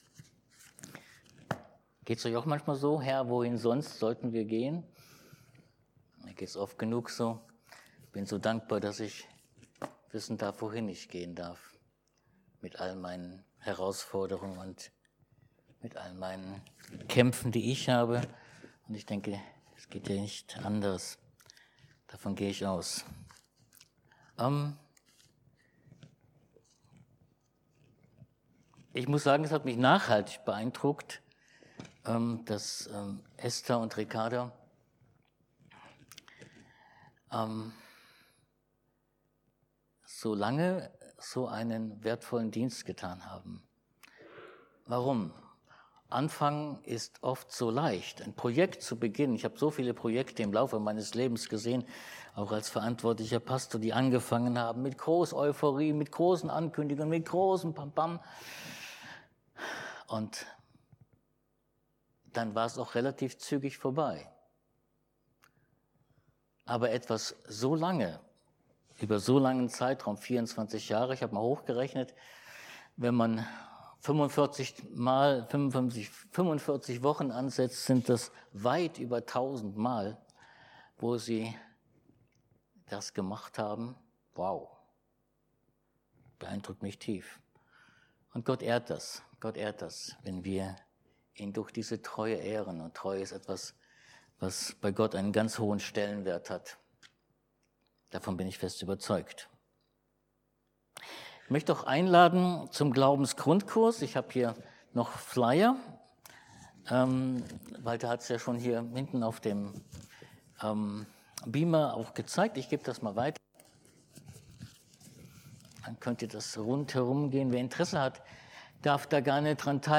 28-30 Dienstart: Predigt Die Schaubrote und der innere Hunger.